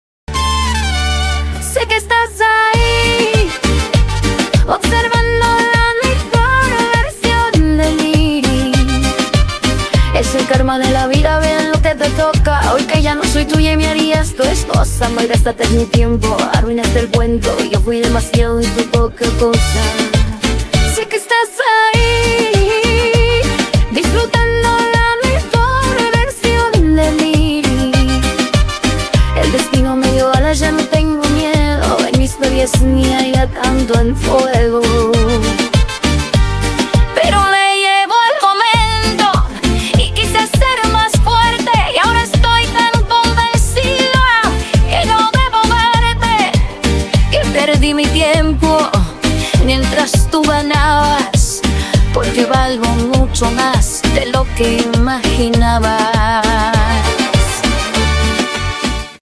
Cumbia Argentina
Musica regional popular Mexicana Argentina